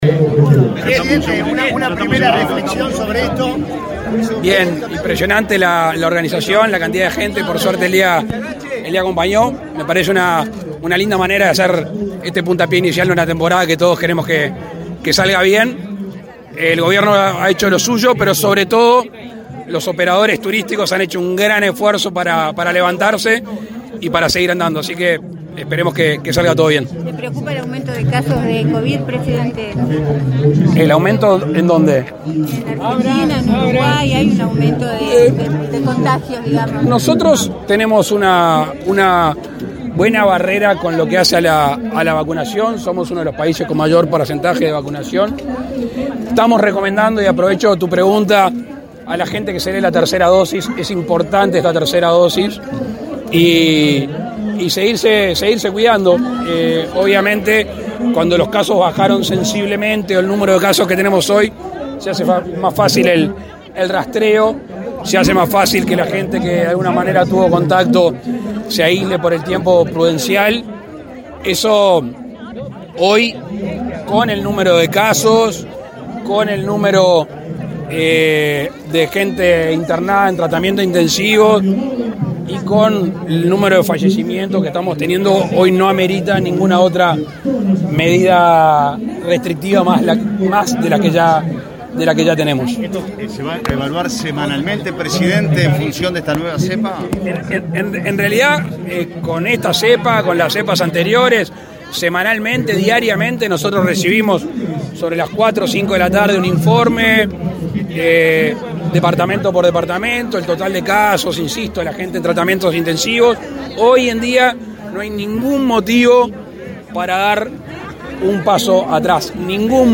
Declaraciones del presidente Lacalle Pou a la prensa
El presidente Luis Lacalle Pou participó este sábado 4 en la 22.ª edición de la Fiesta de la Paella Gigante en Piriápolis, Maldonado, y, luego,